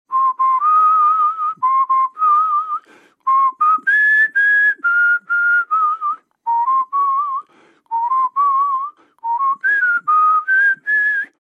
Звук мужского свиста колыбельной мелодии